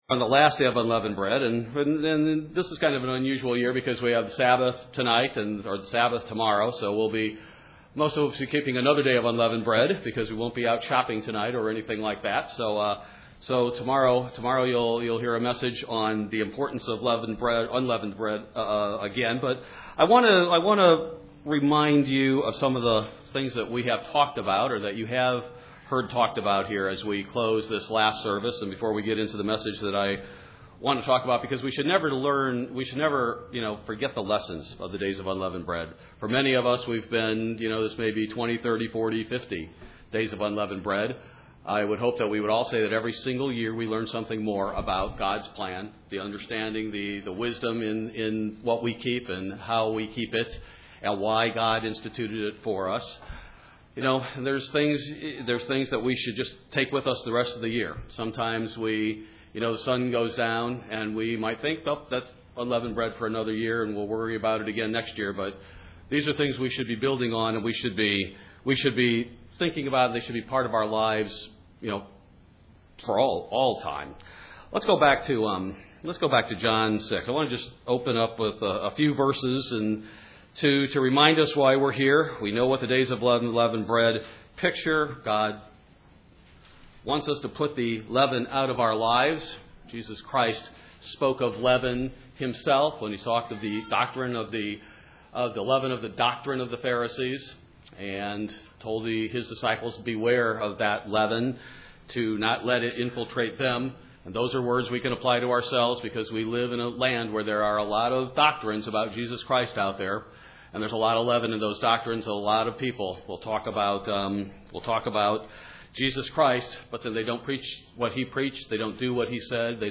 Yet there are some very sober lessons to learn in this time as well. We are told to remember and are given examples in the Bible to teach us these lessons. This message was given on the Last Day of Unleavened Bread.